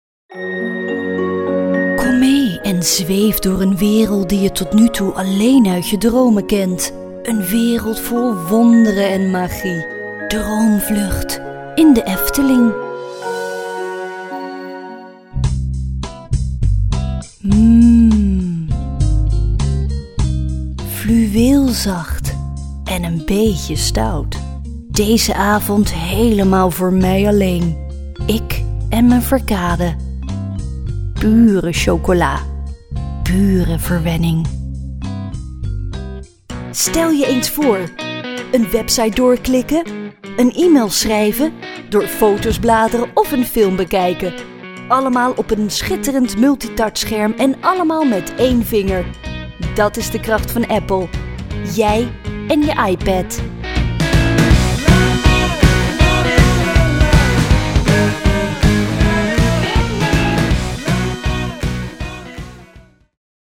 Recording in our own professional studio, we deliver quality clean and crisp tracks.
Sprechprobe: Werbung (Muttersprache):
Smooth, professional, playful, natural, Dutch.